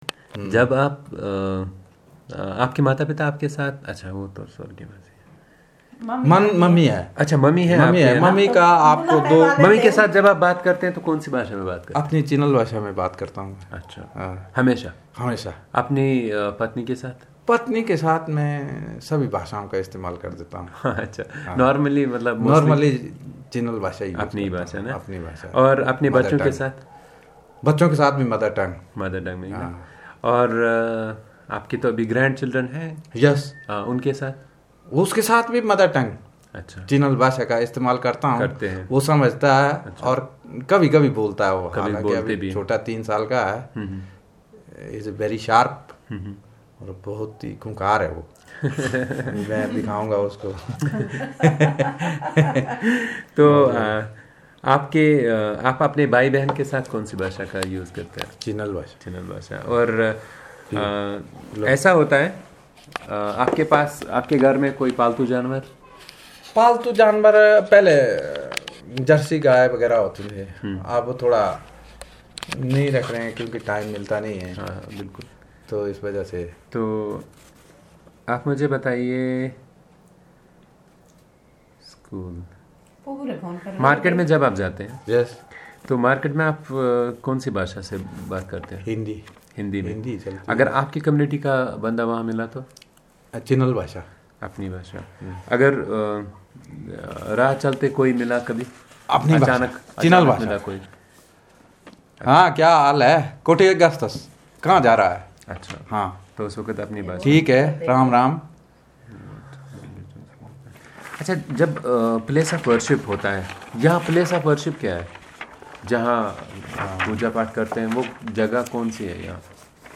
Personal narrative on personal and social information in Chinali